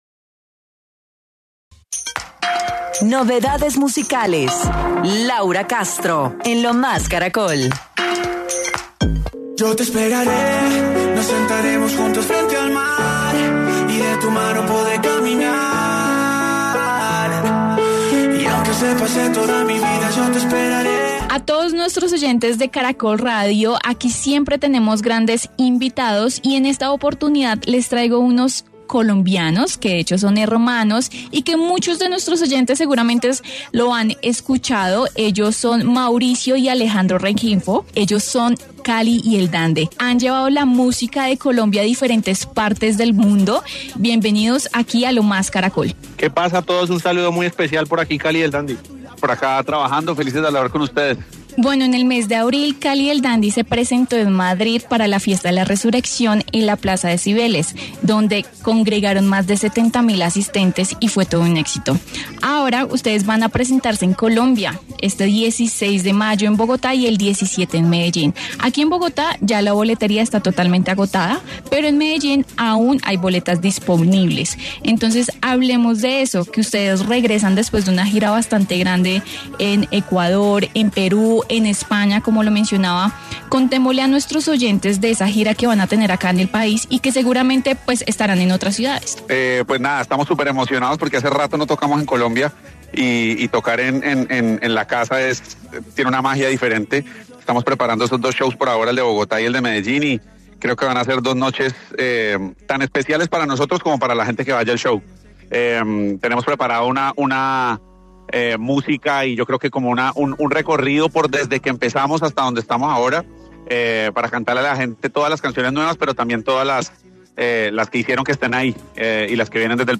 En los micrófonos de Caracol Radio, los colombianos dieron un adelanto de lo que será el "Dejavú Tour 2025” que prometerá nostalgia y recuerdos, “tocar en casa tiene una magia diferente, van a ser dos noches inolvidables para nosotros como para el público, tenemos preparado un recorrido desde los inicios hasta lo de ahora acompañado de invitados, será un show especial”, aseguró Cali.